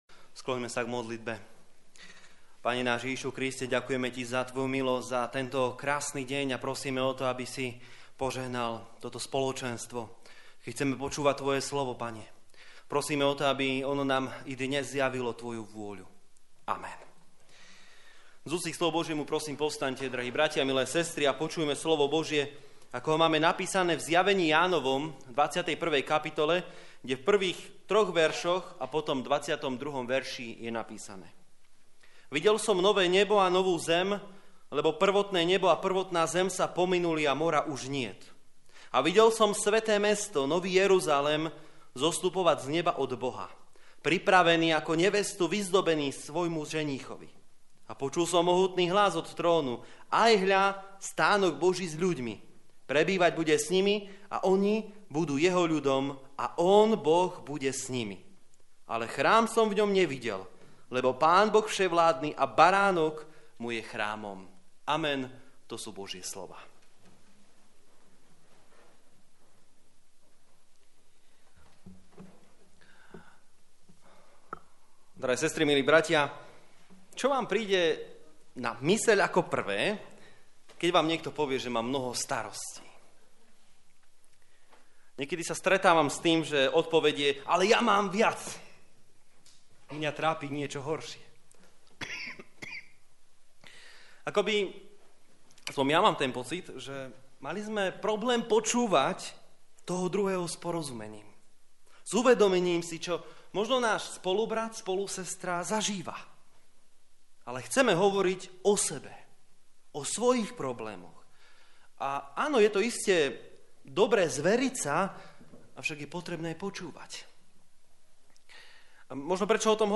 15. nedeľa po Svätej Trojici - Pamiatka posvätenia chrámu Božieho v Kalinove + Poďakovanie za úrody